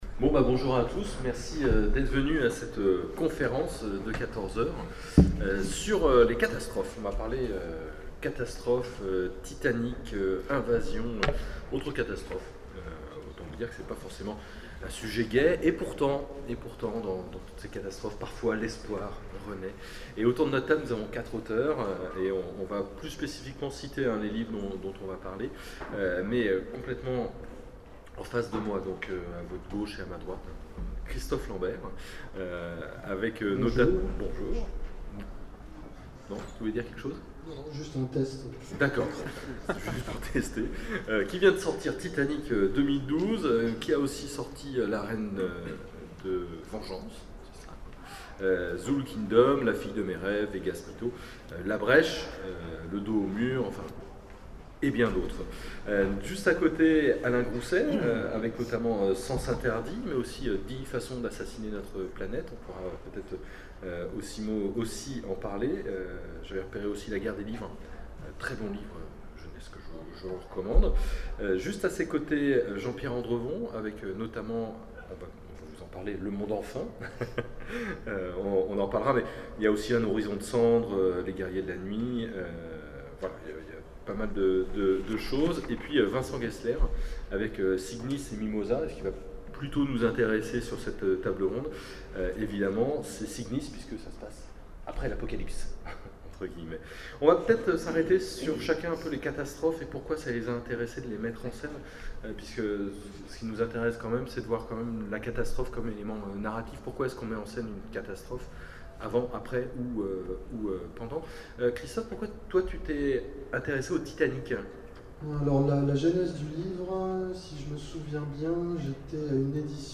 Imaginales 2012 : Conférence Catastrophes en série...